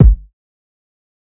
OZ-Kick (Killer).wav